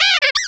pokeemerald / sound / direct_sound_samples / cries / glameow.aif